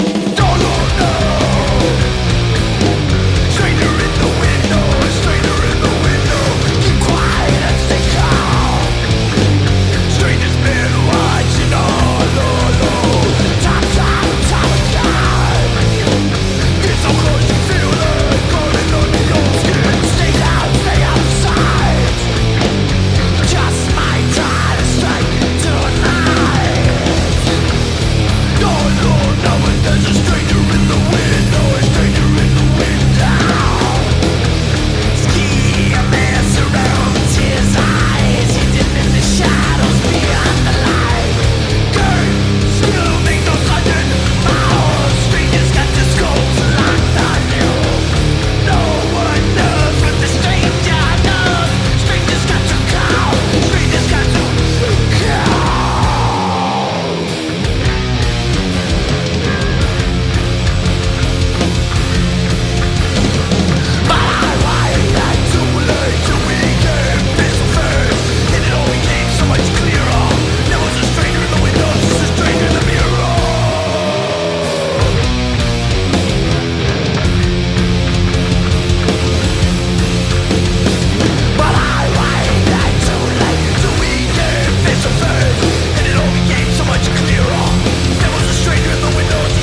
ROCK / 90''S～ / DISCO / DANCE CLASSIC